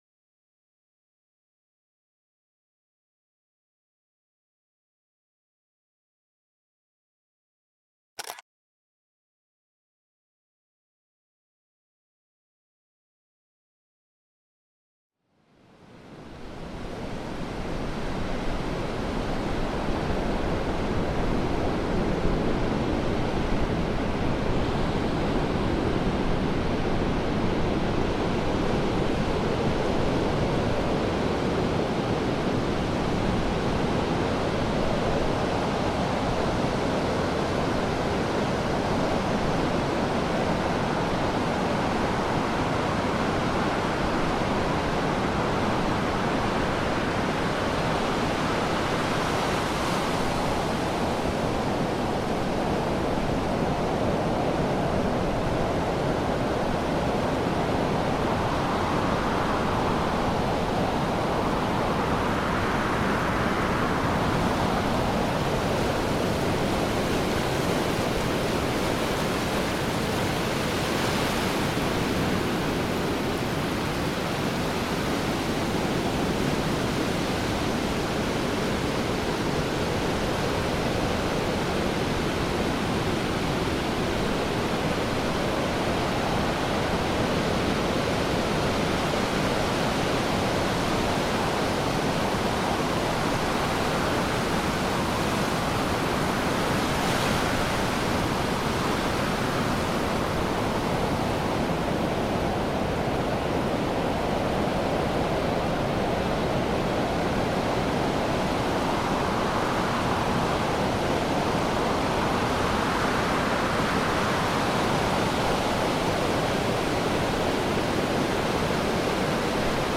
MYSTISCHES FELS-FLÜSTERN: Höhlenrauschen-Flüstern mit sprechenden Tropfen